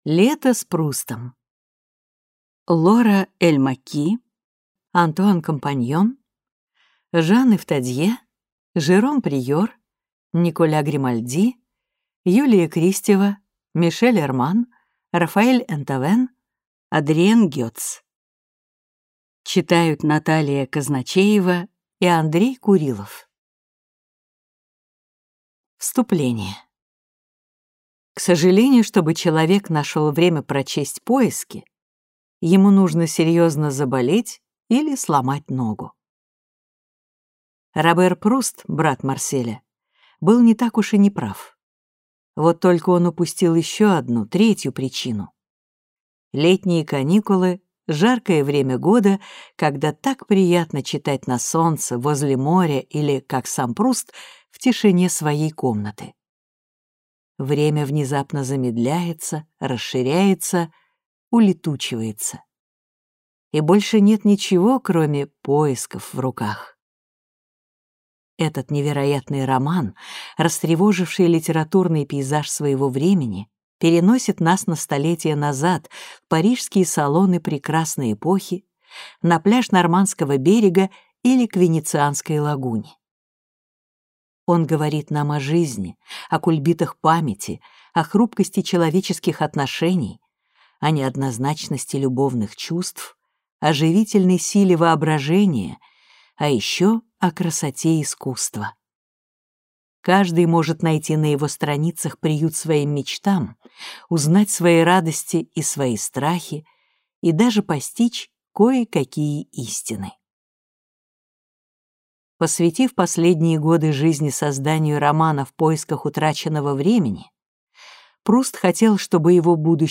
Аудиокнига Лето с Прустом | Библиотека аудиокниг
Прослушать и бесплатно скачать фрагмент аудиокниги